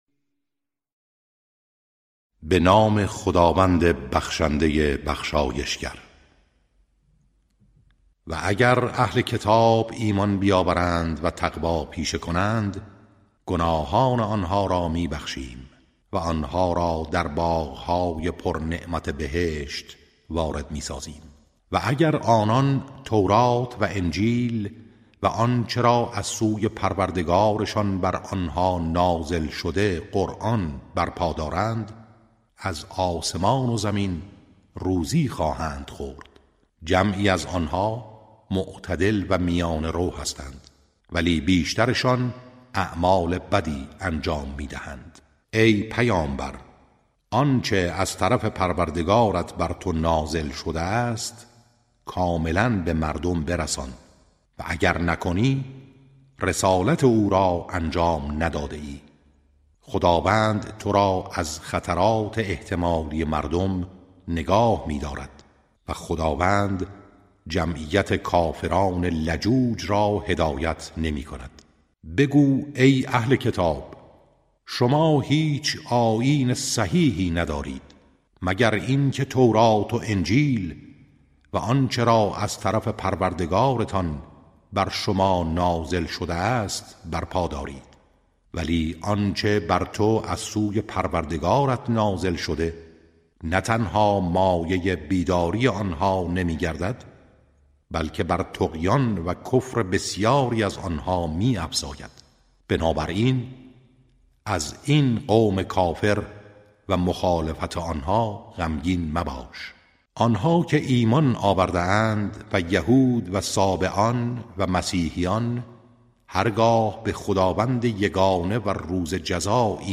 ترتیل صفحه 119 از سوره مائده(جزء ششم)